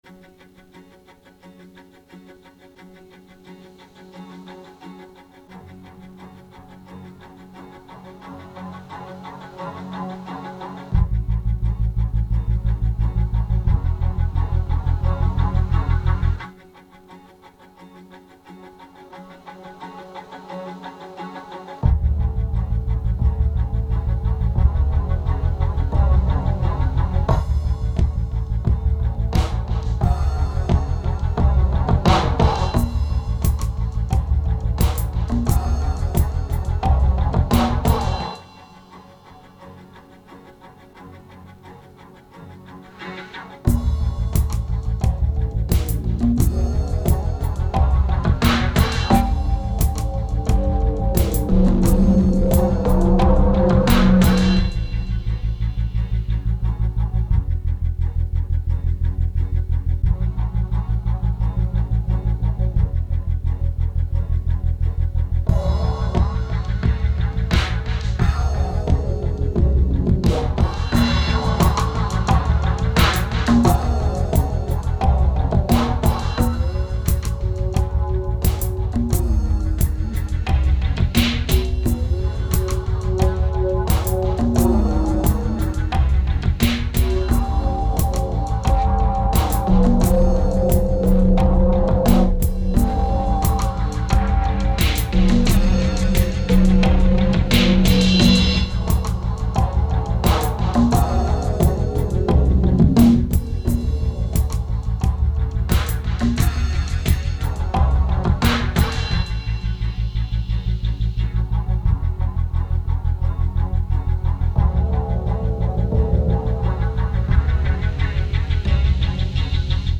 2014📈 - -74%🤔 - 88BPM🔊 - 2008-10-21📅 - -399🌟